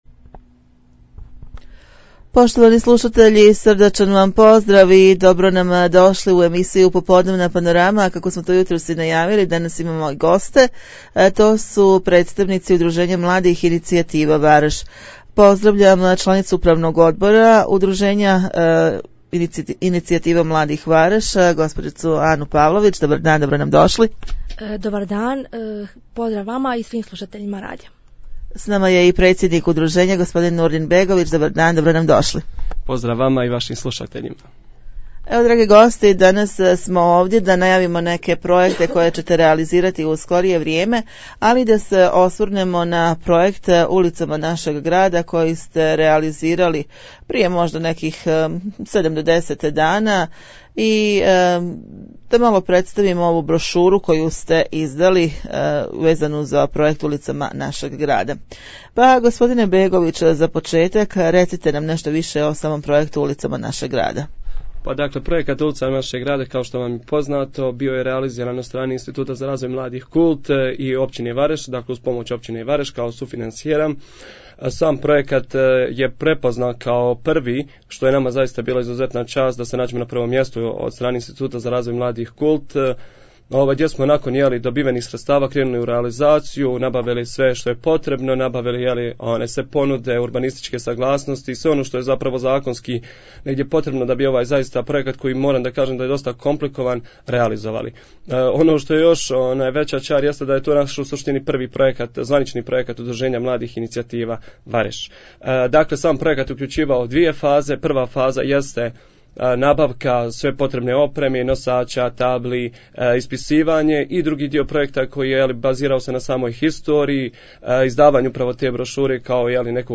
Razgovor s članovima Udruženja mladih "Inicijativa" Vareš